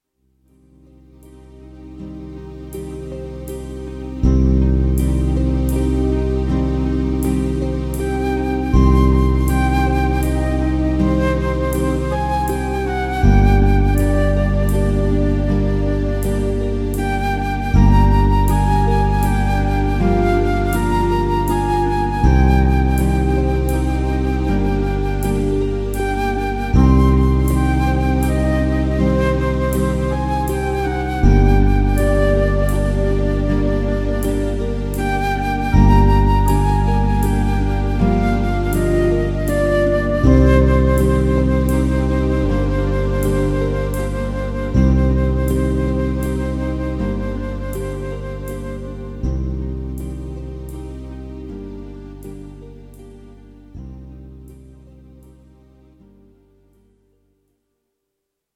It *does* sound quite dreamy.